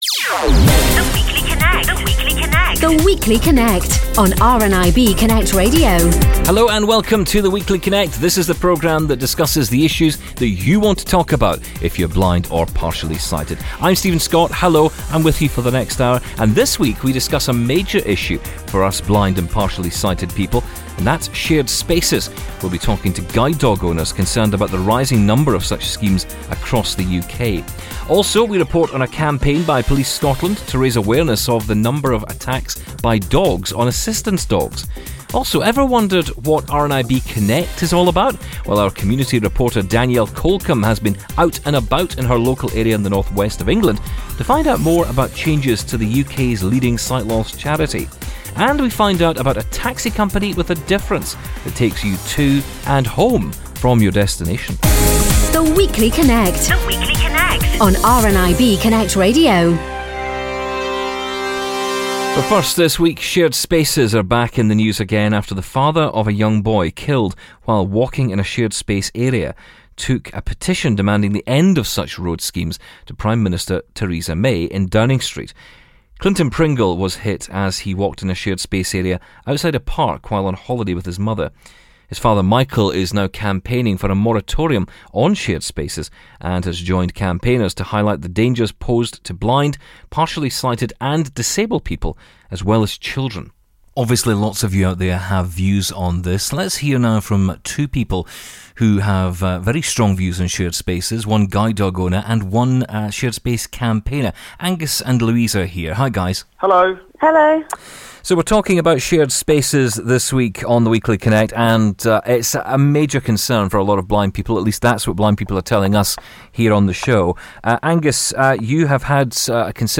On the show this week we discuss a major issue for blind and partially sighted people – Shared Spaces. We’ll be talking to guide dog owners concerned about the rising number of such schemes across the UK.